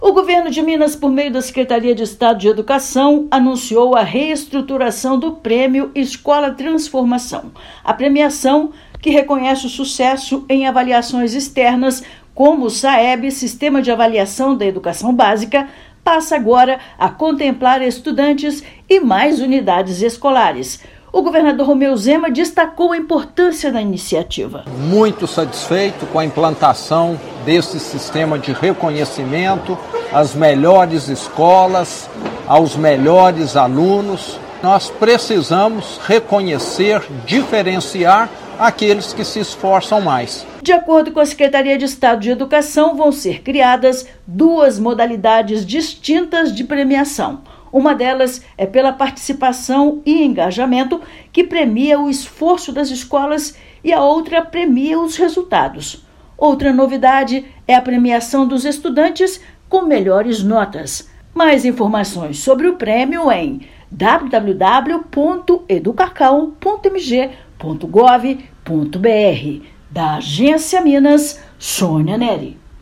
[RÁDIO] Governo de Minas anuncia novo formato do Prêmio Escola Transformação 2025
Programa passará a reconhecer o desempenho de estudantes e escolas estaduais. Ouça matéria de rádio.